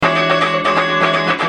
Free MP3 funk music guitars loops & sounds 1
Guitare loop - funk 21